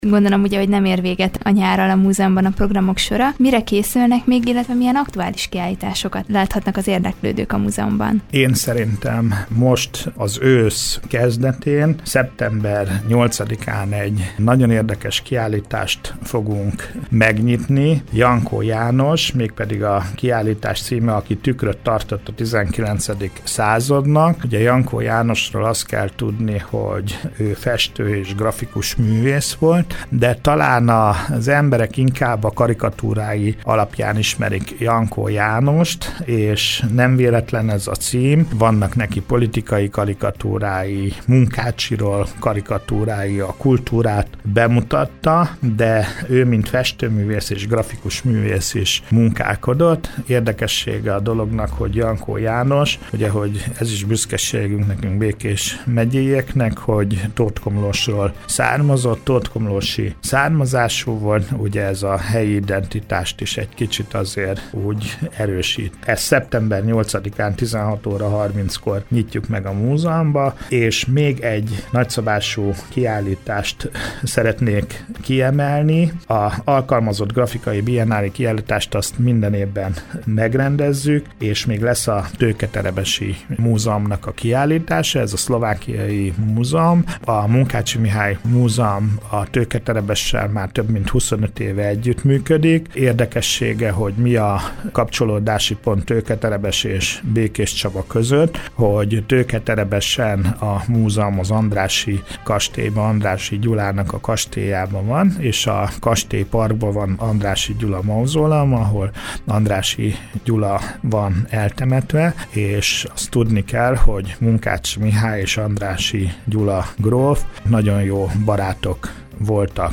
Stúdiónk vendége volt